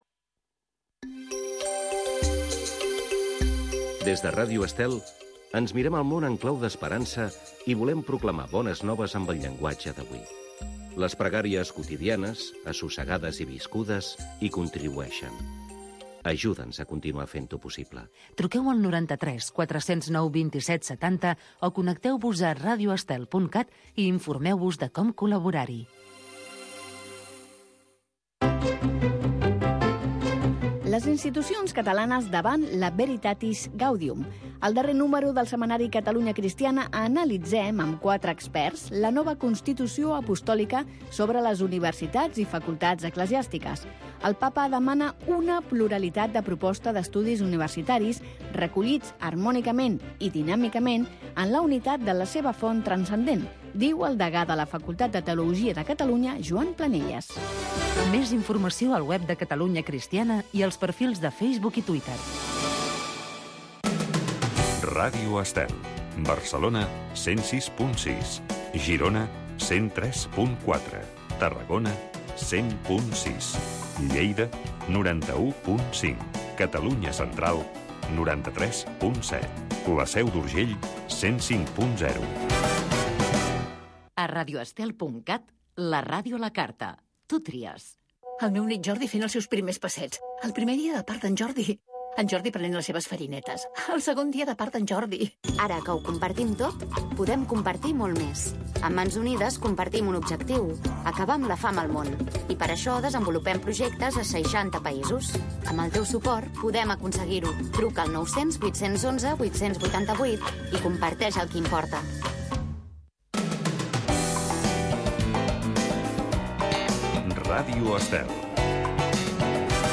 Ens fem sentir. Programa de diàleg amb adolescents i joves sobre la vida i la fe. 3 joves i un mossén, tractaran temes d'Actualitat.